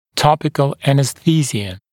[‘tɔpɪkl ˌænɪs’θiːzɪə][‘топикл ˌэнис’си:зиэ]аппликационная анестезия